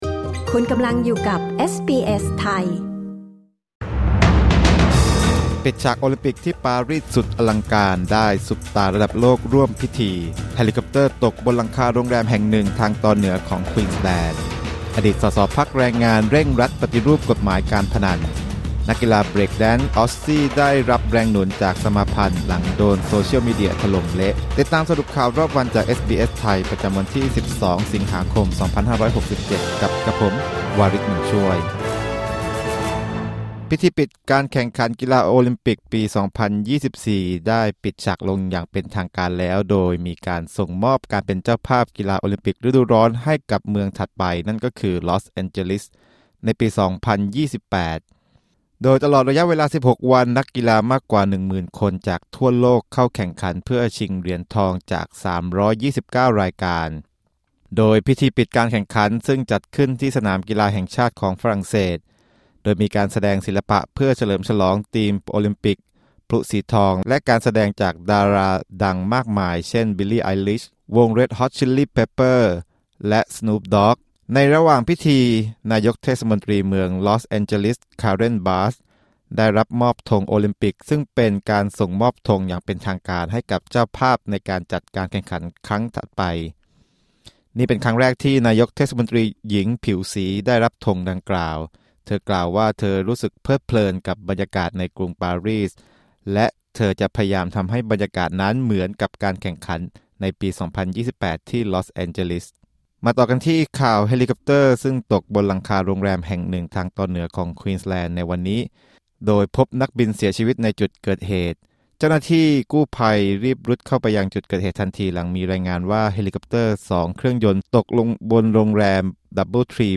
สรุปข่าวรอบวัน 12 สิงหาคม 2567
คลิก ▶ ด้านบนเพื่อฟังรายงานข่าว